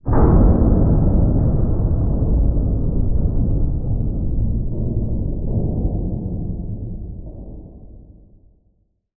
Commotion18.ogg